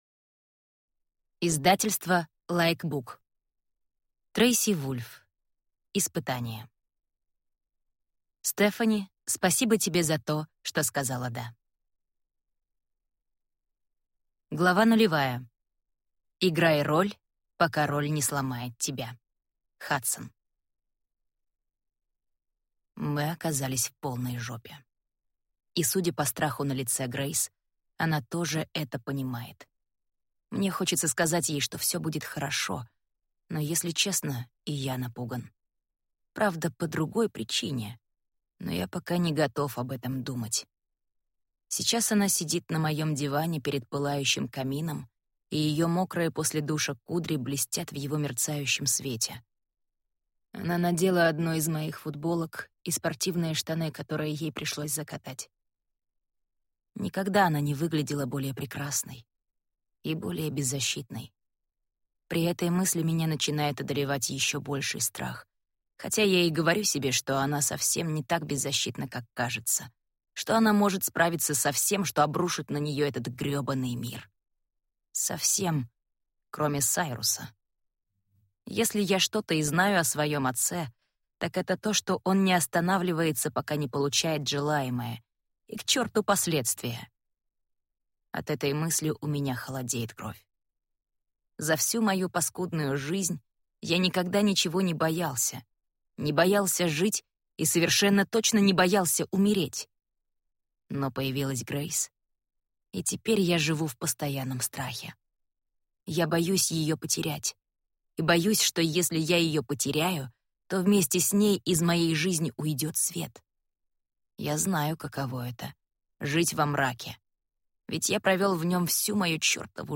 Аудиокнига Испытание | Библиотека аудиокниг
Прослушать и бесплатно скачать фрагмент аудиокниги